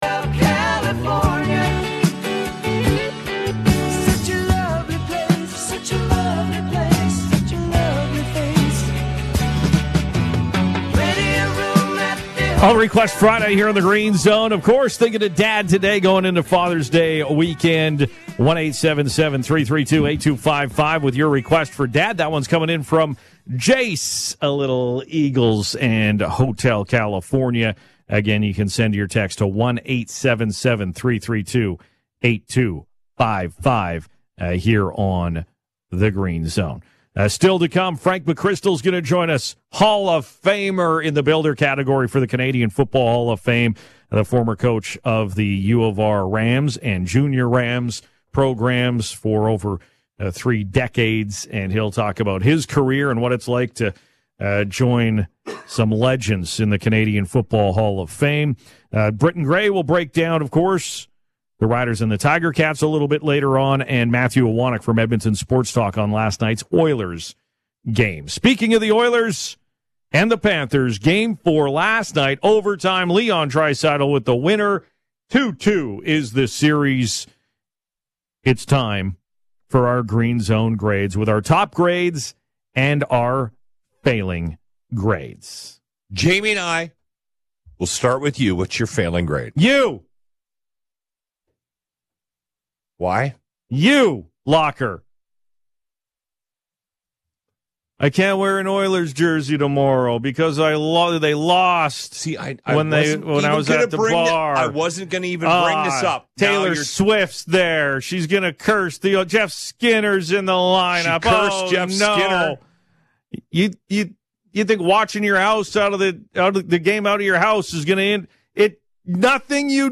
He joins us in studio now.